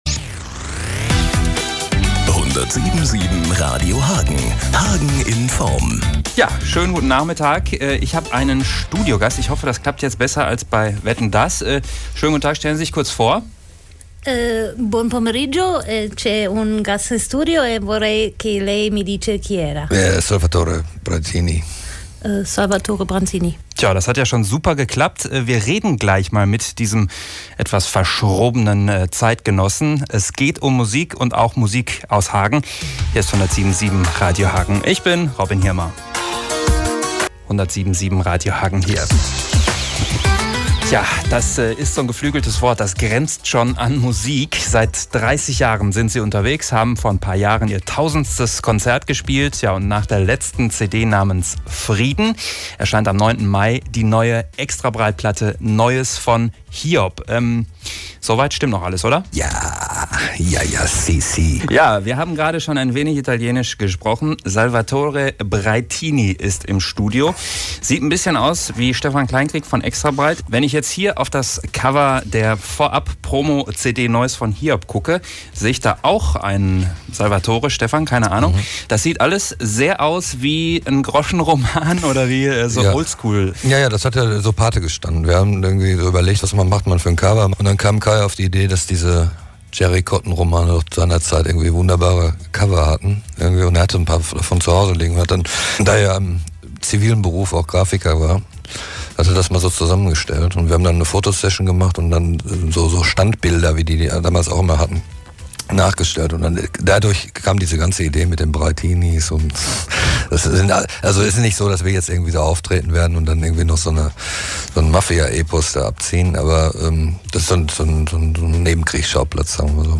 im Interview on air
Das besondere bei diesem Interview ist, dass ganz ganz kurz sogar der Refrain von “LÄRM” zu hören ist.